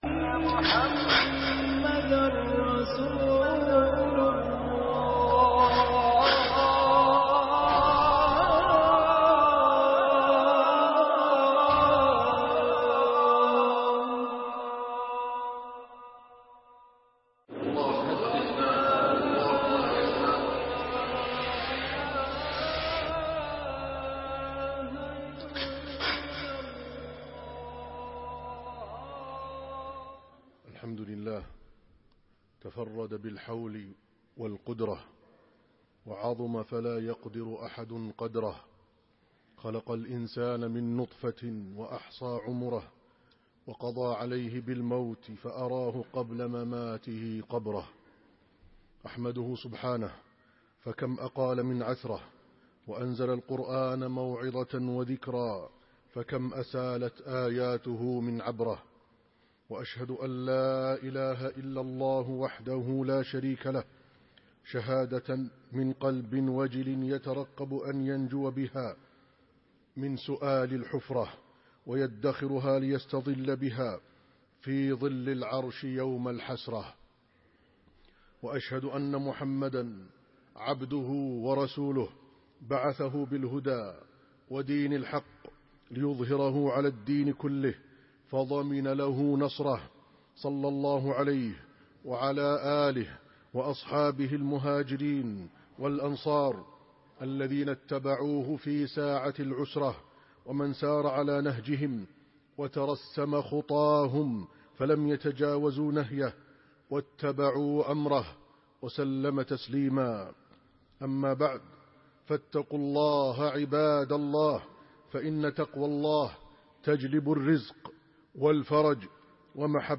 سؤال الملكين ( 6/3/2014) خطب منبرية - الشيخ عادل الكلباني